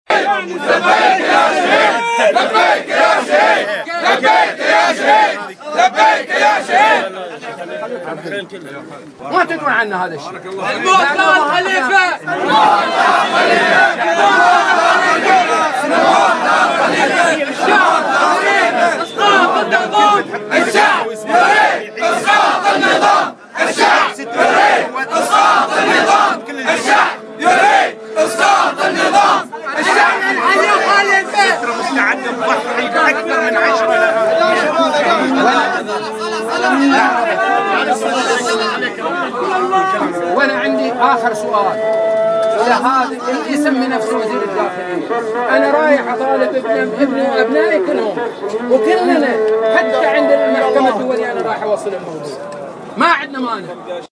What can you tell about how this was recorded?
Father at Funeral and Chants